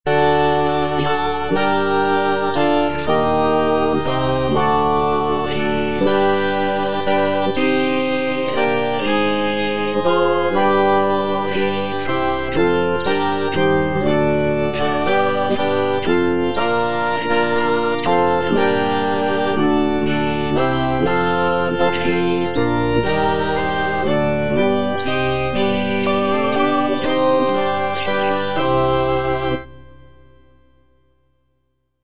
Choralplayer playing Stabat Mater by G.P. da Palestrina based on the edition CPDL #48614
G.P. DA PALESTRINA - STABAT MATER Eja Mater, fons amoris (All voices) Ads stop: auto-stop
sacred choral work
Composed in the late 16th century, Palestrina's setting of the Stabat Mater is known for its emotional depth, intricate polyphonic textures, and expressive harmonies.